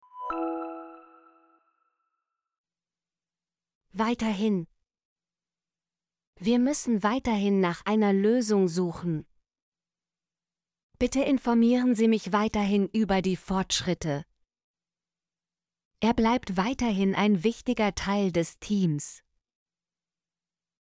weiterhin - Example Sentences & Pronunciation, German Frequency List